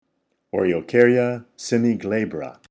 Pronunciation/Pronunciación:
O-re-o-cár-ya  se-mi-glà-bra